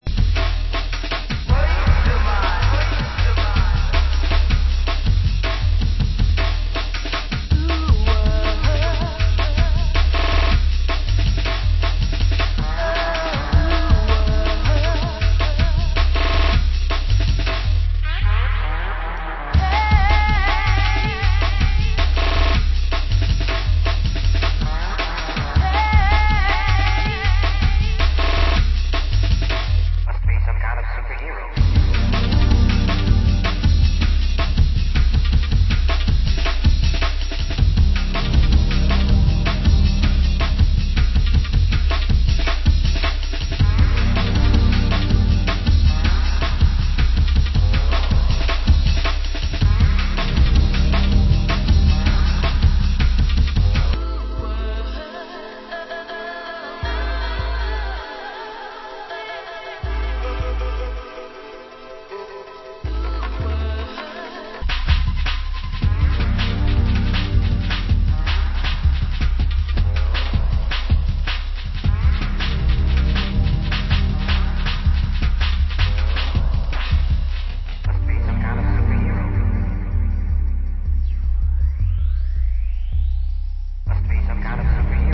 Genre: Jungle